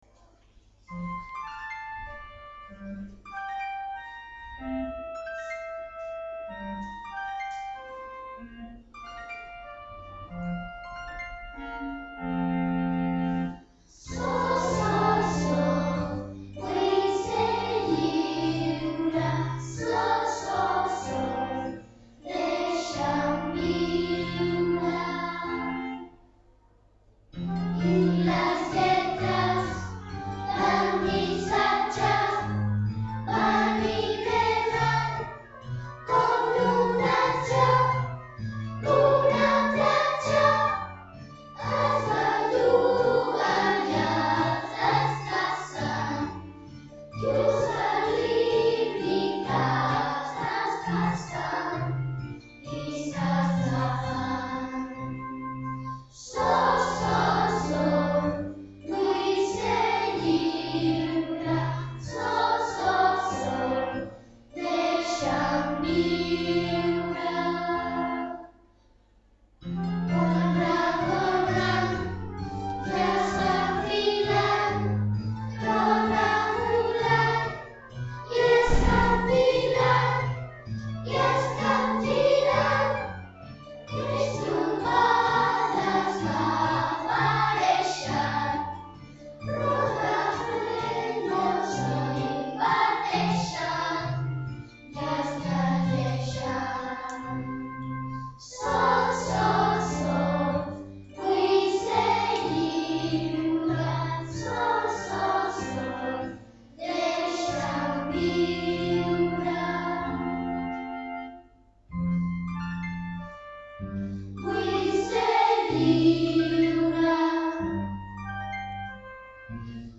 A continuació us posem totes les cançons de la cantata el Ratolí Electrònic text d’en Jesús Nieto i música d’en Ricard Gimeno que els alumnes de 4t hem enregistrat a l’escola .